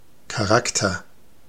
Ääntäminen
Synonyymit symbolise symbolize token emblem Ääntäminen US Tuntematon aksentti: IPA : /ˈsɪmbəl/ Haettu sana löytyi näillä lähdekielillä: englanti Käännös Ääninäyte Substantiivit 1.